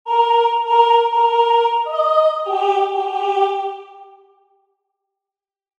She hums a strain of the hymn Varina, and as she comes along, she touches the wall lightly with her white finger tips and walks with a hesitating step as if the floor were slippery, or as if she were accustomed to find her way more by the sense of touch than by that of sight.